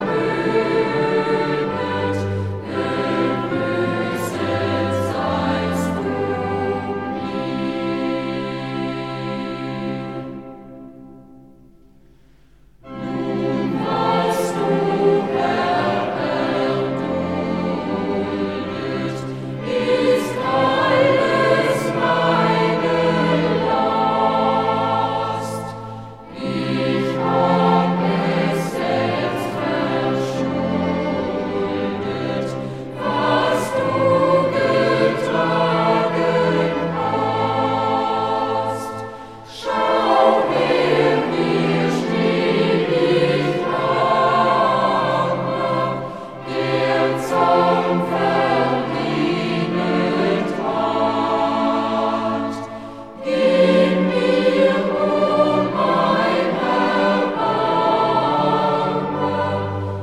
Choräle & Heilslieder, Instrumental